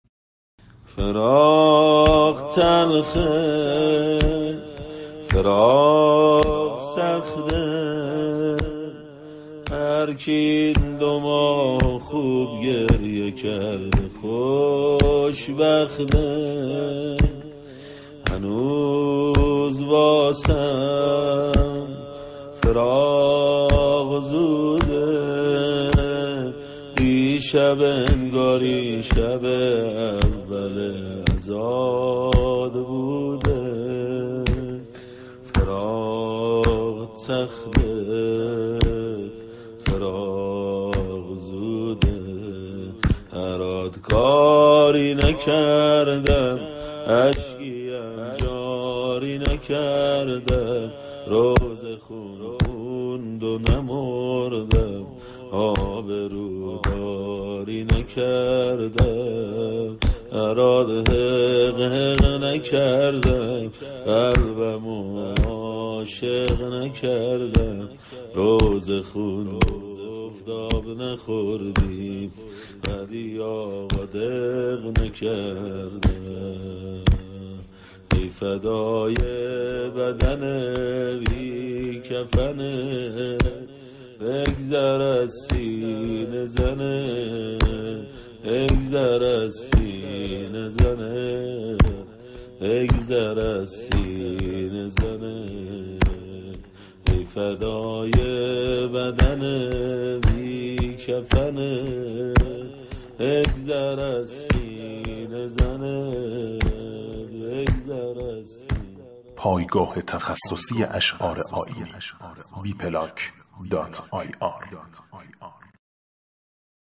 شور ، زمینه